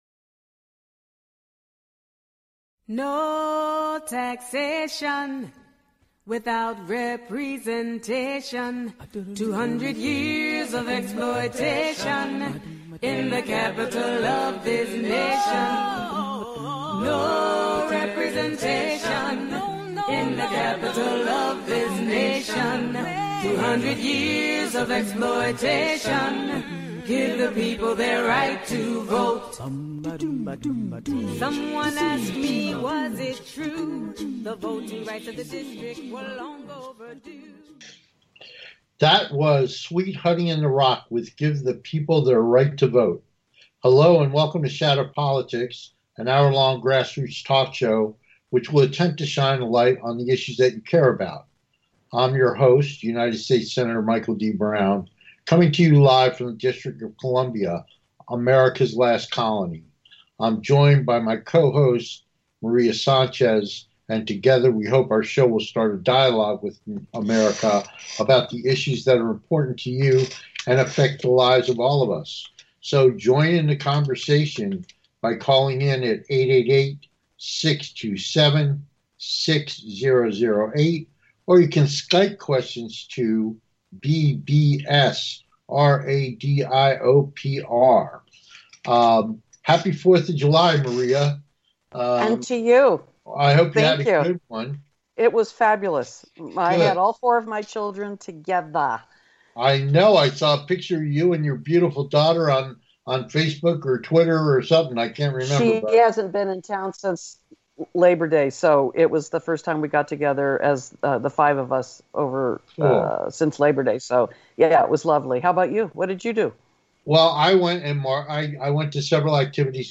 Guest, Medea Benjamin